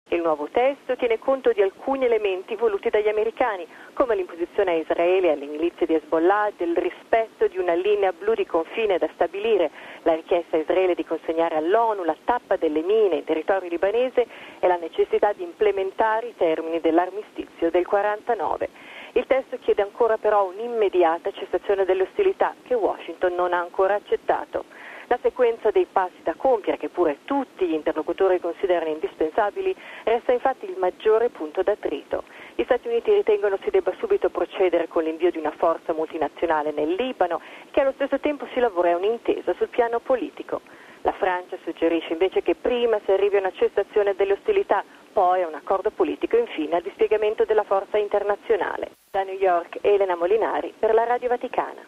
Il servizio, da New York,